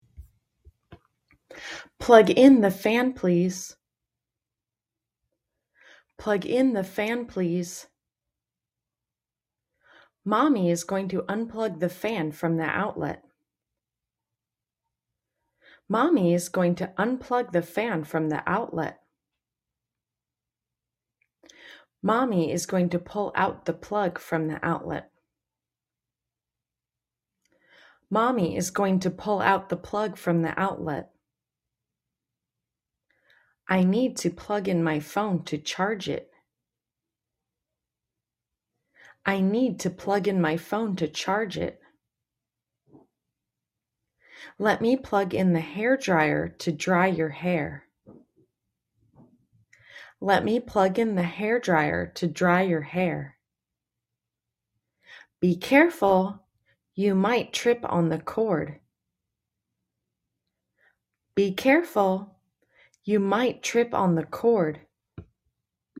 Mẫu hội thoại xoay quanh cuộc sống hằng ngày của con, được ghi âm trực tiếp từ người Mỹ không những giúp phụ huynh ôn tập mà còn giúp luyện nói, phát âm và nghe.